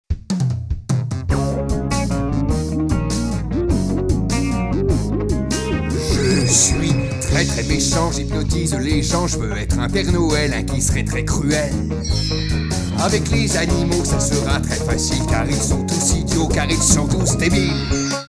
En avant première, un extrait des chansons de Bartacus, Lili et Zélectron !